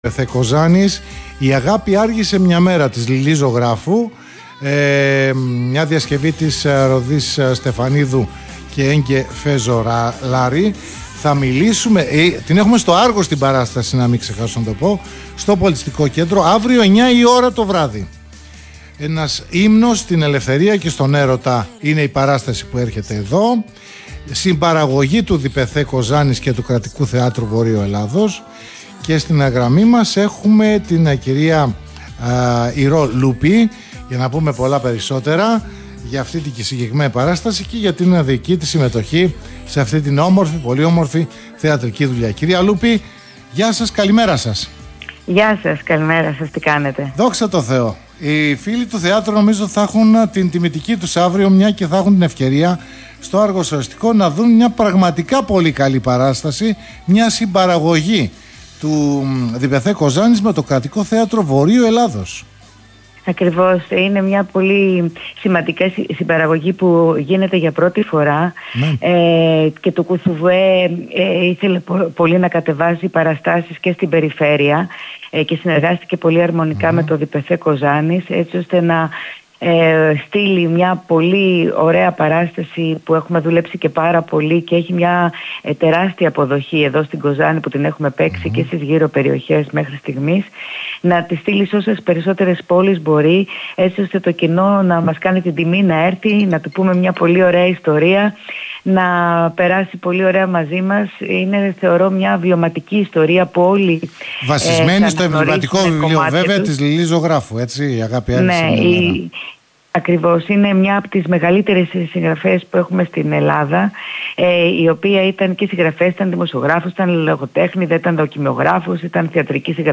Η συνέντευξη δόθηκε χθες 27/11: